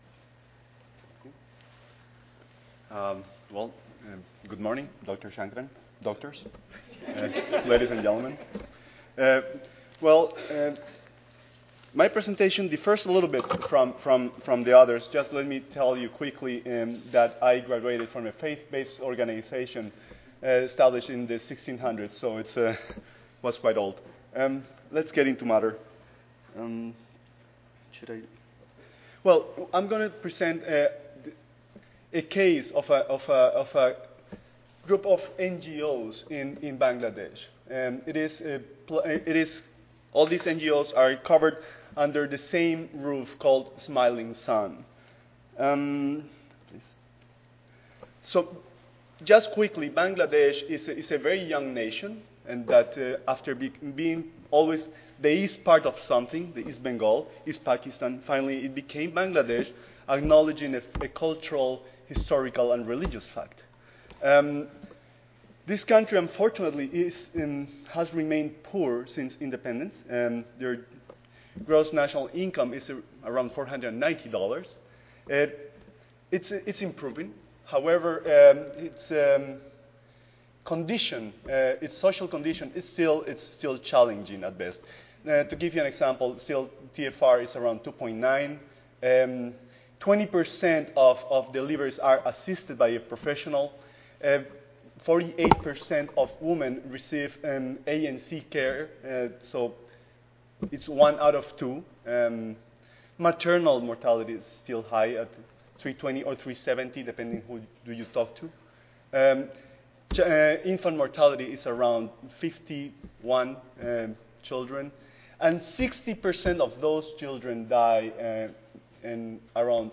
The objective of the Smiling Sun Franchise Program (SSFP) is to convert 30 local NGOs that have a network of 319 clinics to a franchise business model that will make available sustainable, high quality reproductive health and primary care services. This presentation discusses the steps taken to develop a viable franchise management organization.